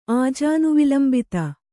♪ ājānuvilambita